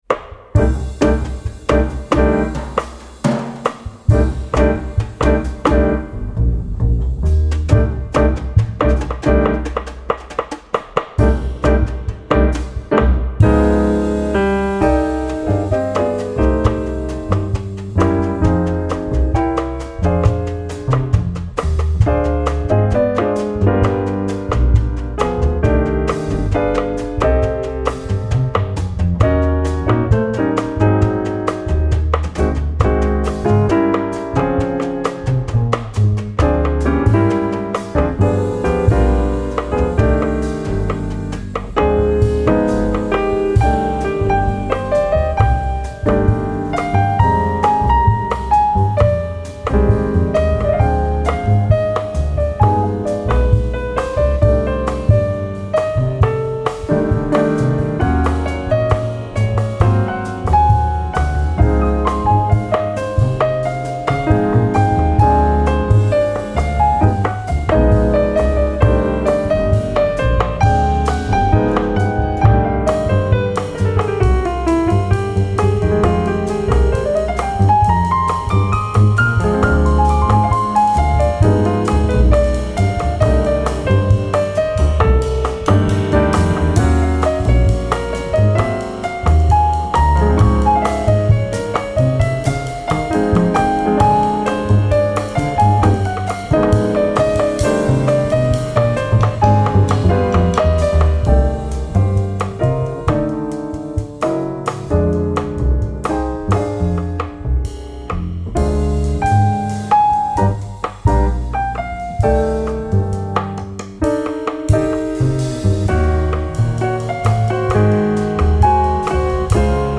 Piano
Kontrabass
Drums